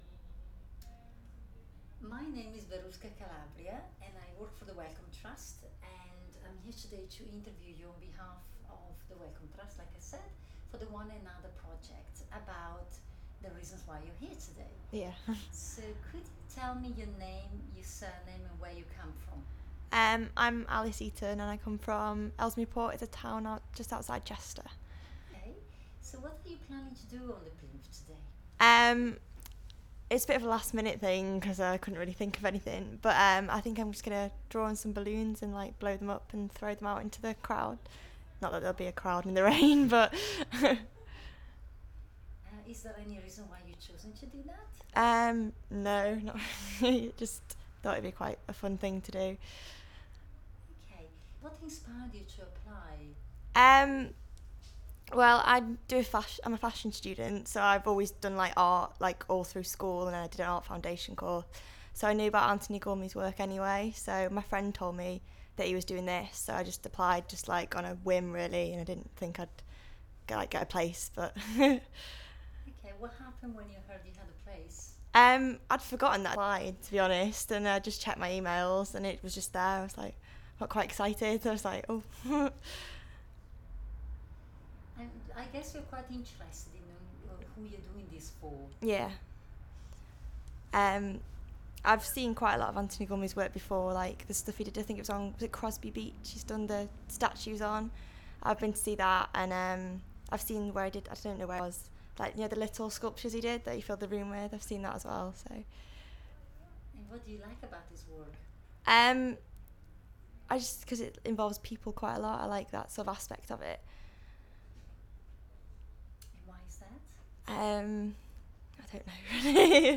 Audio file duration: 00:06:37 Format of original recording: wav 44.1 khz 16 bit MicportPro.